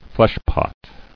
[flesh·pot]